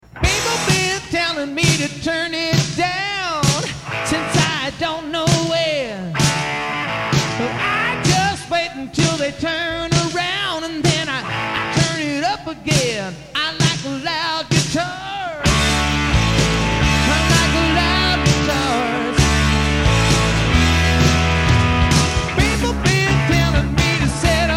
This one was recorded live to cassette (really!)
slide guitar
I played bass.